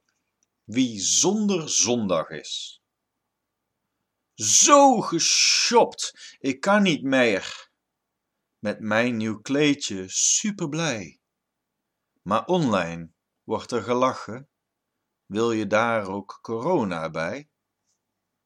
En dus ben ik al maandenlang mijn gedichten en verhalen in aan het spreken en in audiovorm online aan het zetten.